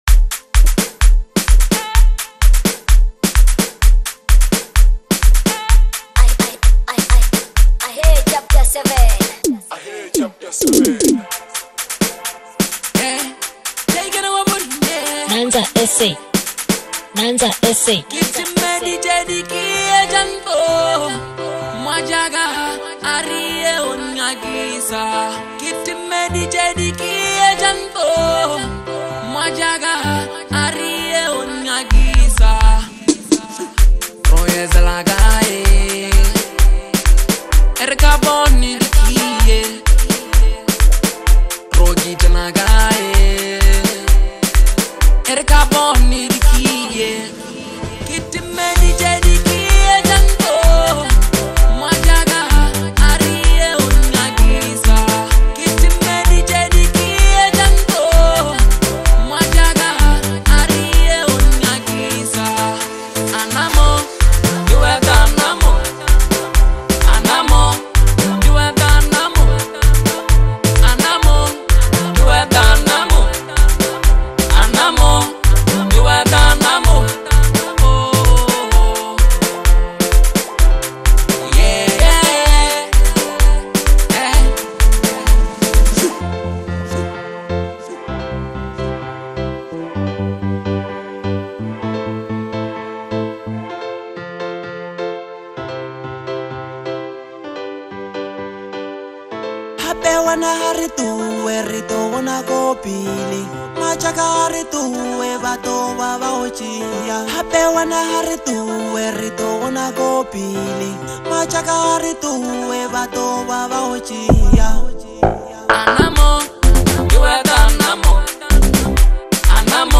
combines powerful lyrics with an infectious beat